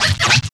108 SCRTCH-R.wav